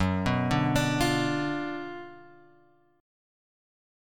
F#M7sus4#5 chord {2 2 0 x 3 1} chord